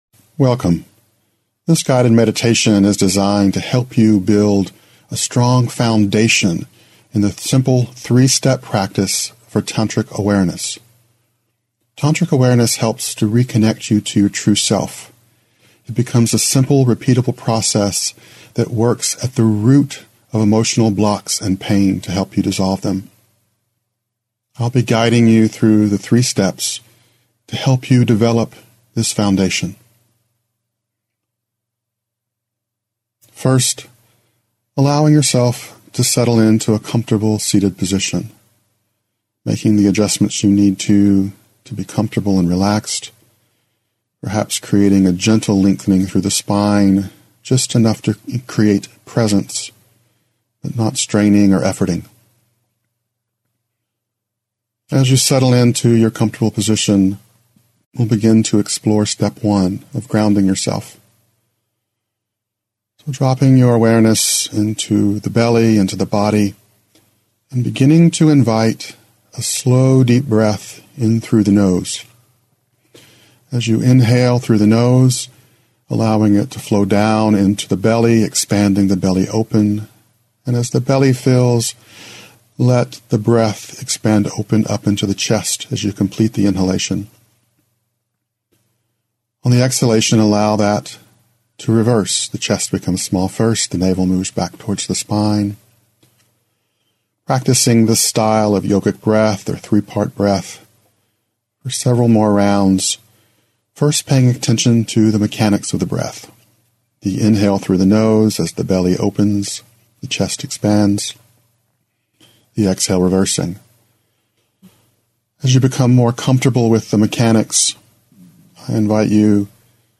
ClearThePath-Guided3StepTantricAwareness.mp3